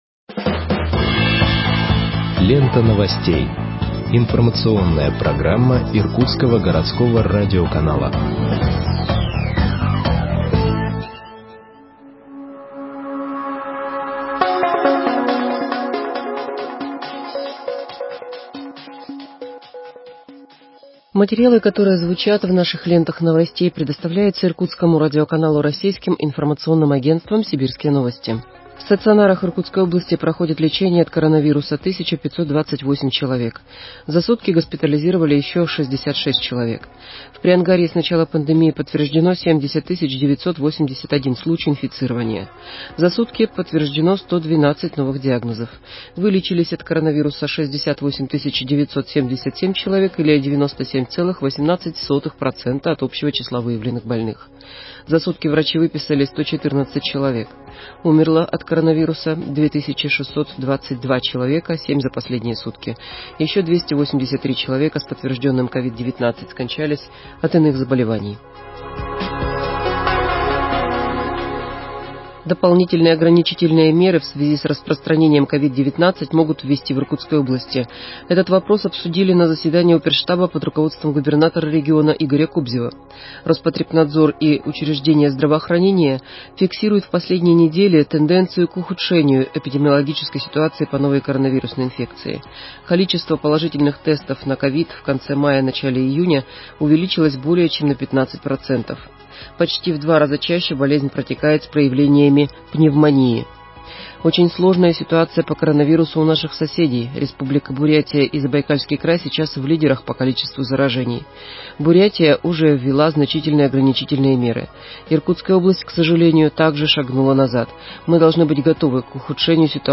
Выпуск новостей в подкастах газеты Иркутск от 10.06.2021 № 2